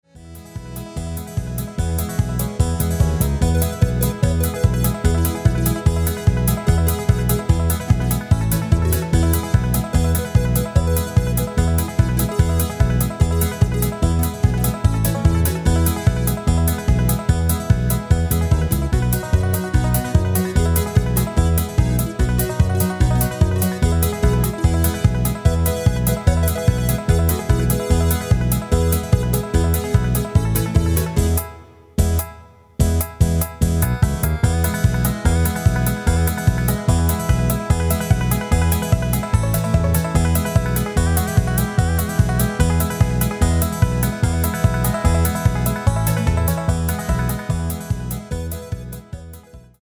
Rubrika: Pop, rock, beat
Karaoke
Úvodní verš se zpívá bez nástrojů
Dohra: harmonika do ztracena